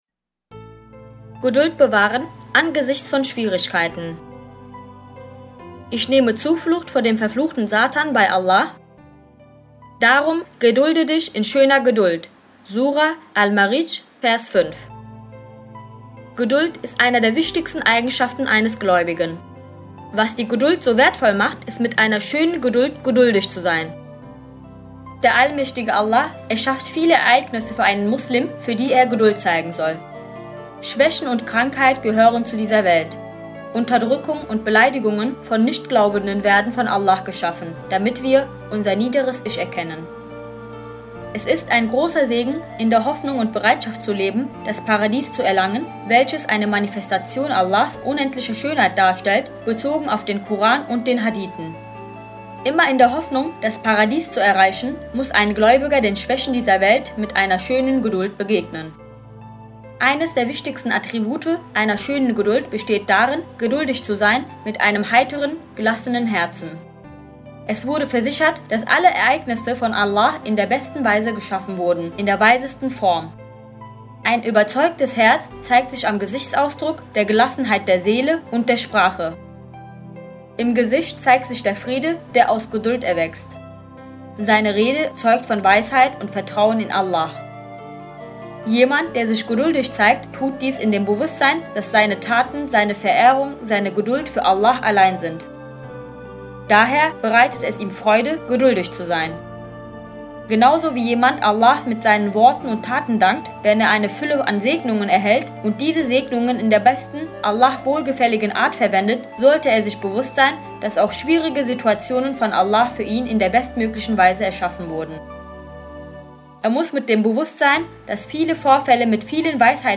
Ausschnitt aus dem Live-Interview von Adnan Oktar bei dem Sender Tempo TV (20 Januar 2009)Adnan Oktar: Das Paradies ist für uns als Gabe erschaffen worden, jedoch nicht alle, die ins Paradies kommen, werden auch den gleichen Genuss empfinden.
Geduld_zeigen_ang_schwierigk_mt_musik.mp3